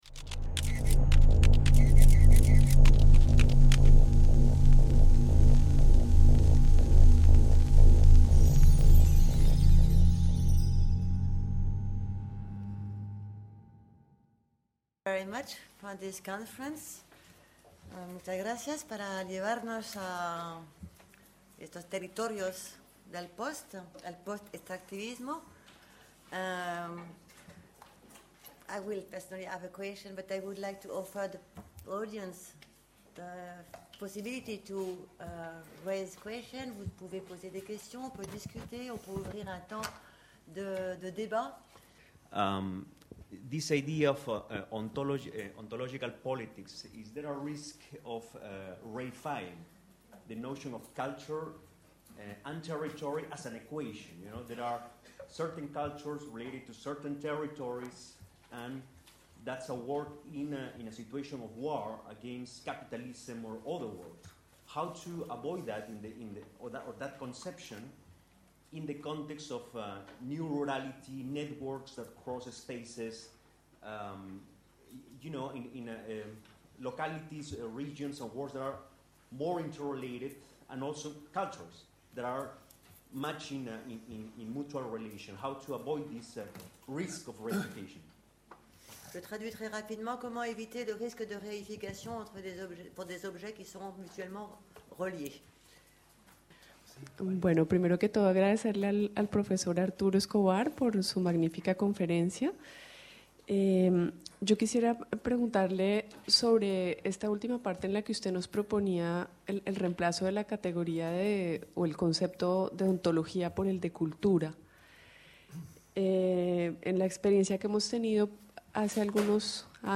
2 - Conférence du Pr. Arturo Escobar (suite) - Questions du public | Canal U